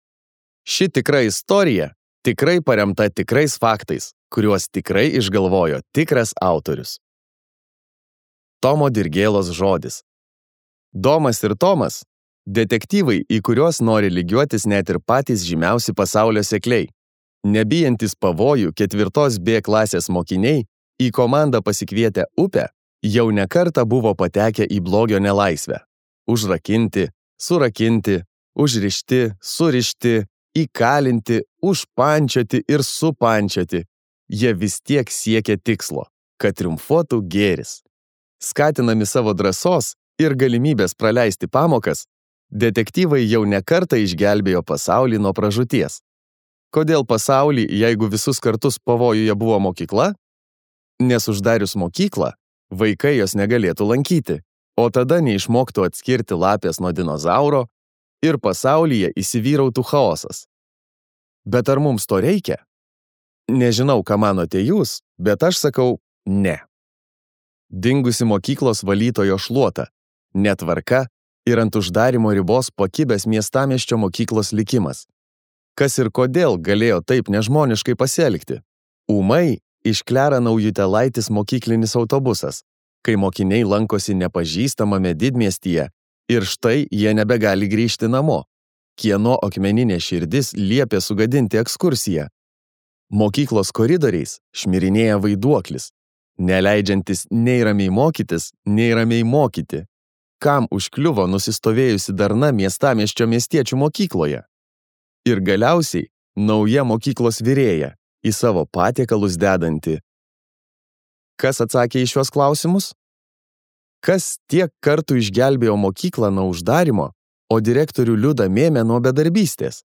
Skaityti ištrauką play 00:00 Share on Facebook Share on Twitter Share on Pinterest Audio Domas ir Tomas.